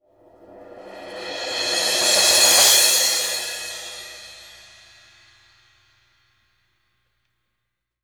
MALLETSO.1-L.wav